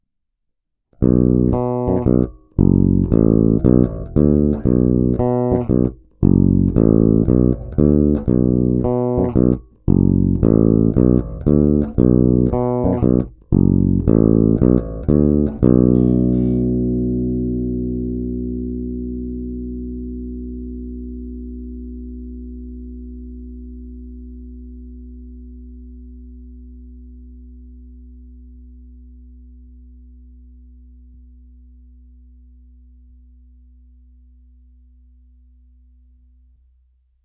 Zvuk má modernější projev, je pěkně tučný, se sametovými nižšími středy, ovšem taky je nepatrně zastřený díky použitým humbuckerům.
Není-li uvedeno jinak, následující nahrávky jsou provedeny rovnou do zvukové karty, s plně otevřenou tónovou clonou a na korekcích jsem trochu přidal jak basy, tak výšky.
Oba snímače